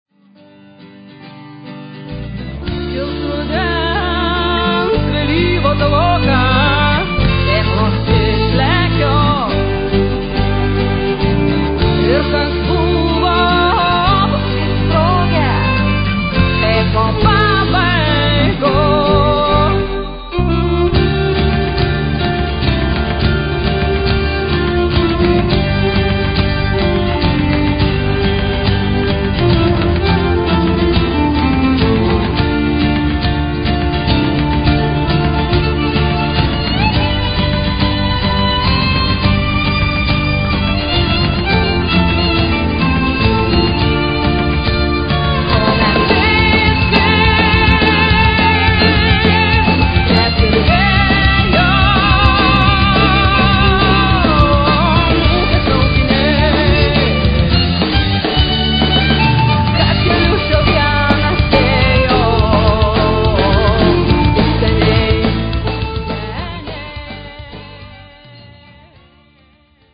Acoustic Guitar, Electric Guitar
Bass
Drums, Percussion
Piano
Violin
Vocals, Acoustic Guitar, Backing Vocals, Flute